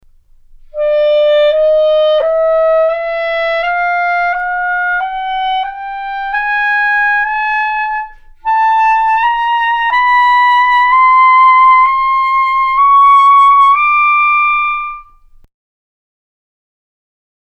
An equidistant scale of sixteen 3/8 tones is represented in Example #93.